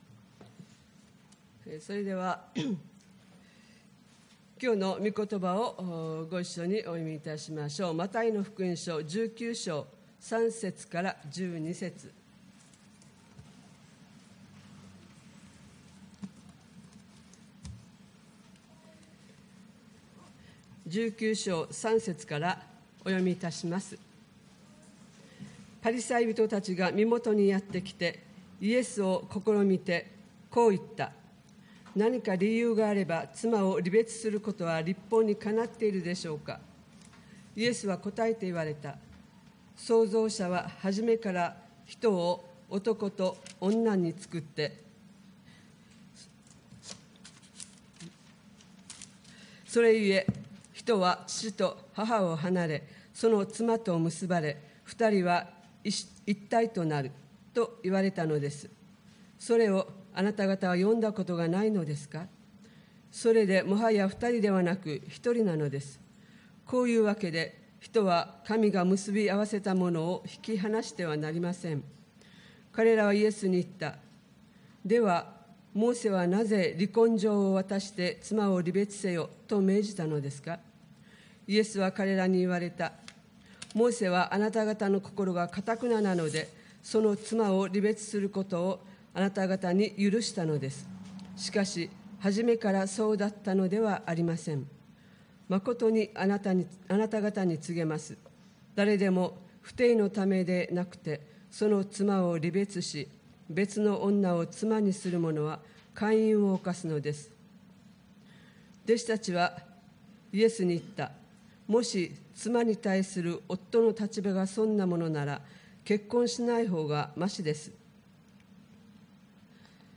礼拝メッセージ(説教)